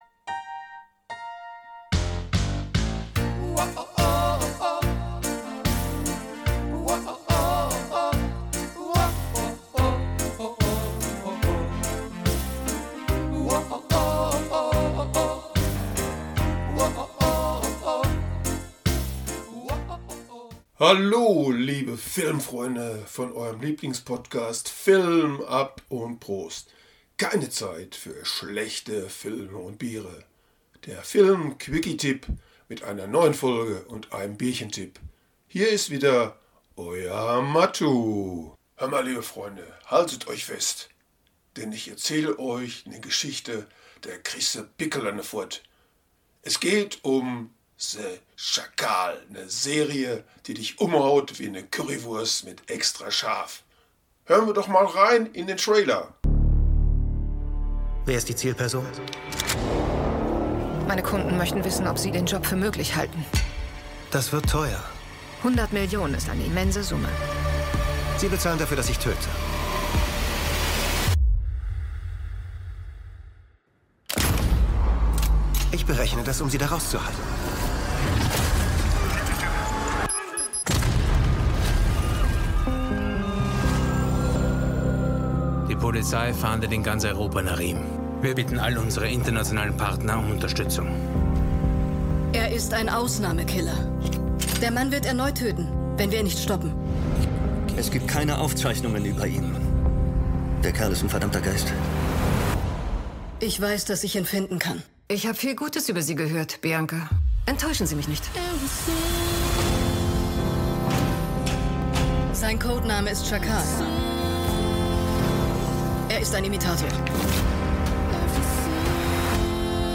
Der Filmquickie - Tipp mit Ruhrpottcharme und lecker Bierchen